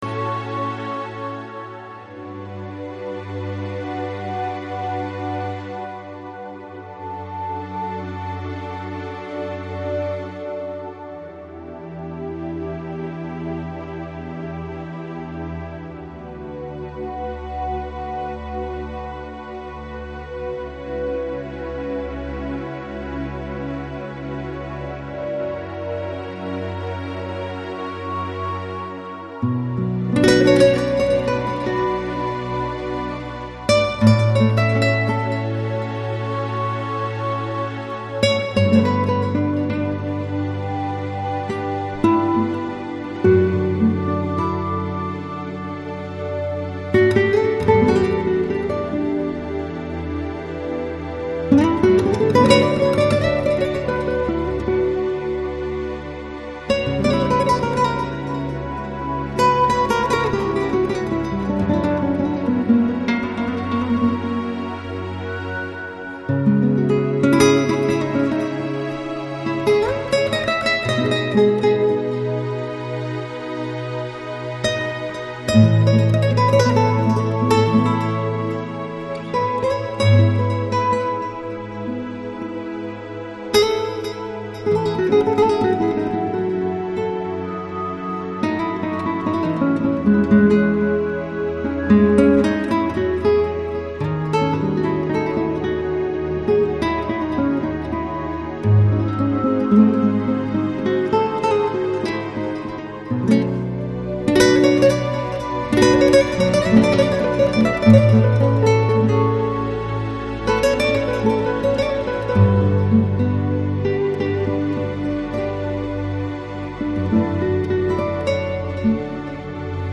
Жанр: Downtempo, Lounge, Chillout, Ambient